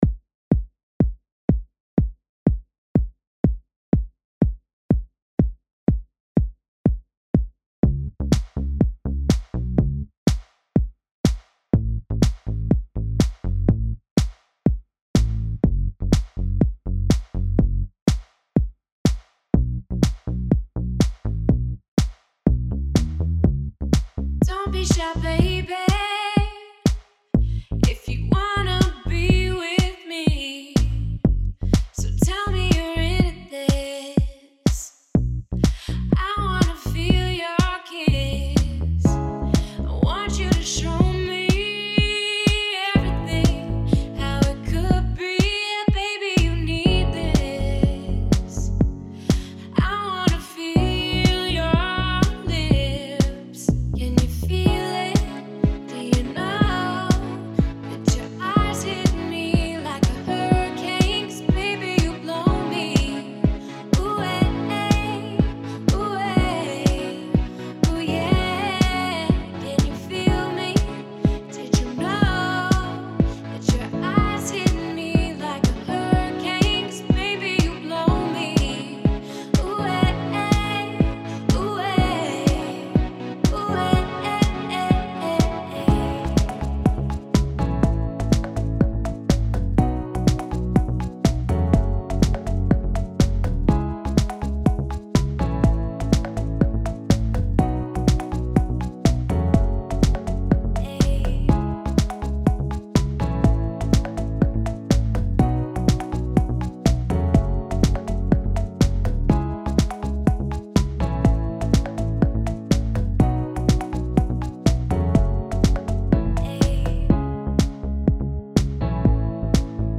Allround-DJ für Tanzmusik und House-Partys